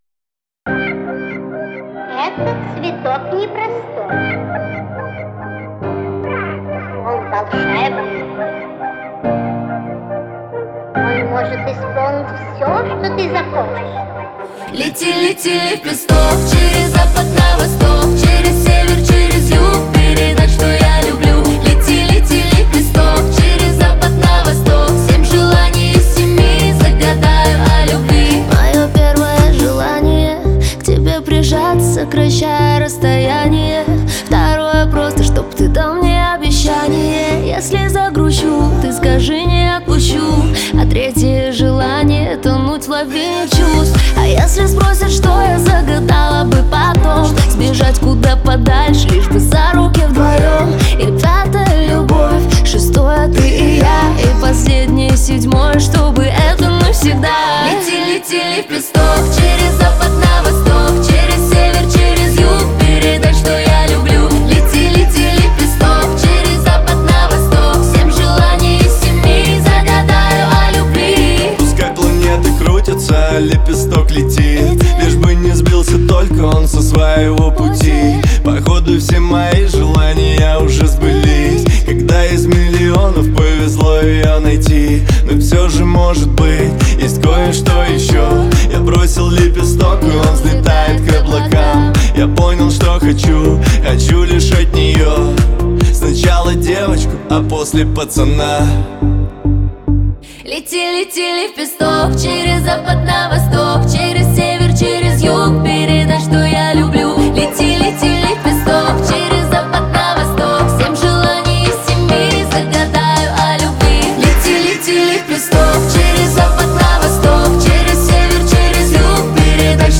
это яркий трек в жанре поп с элементами R&B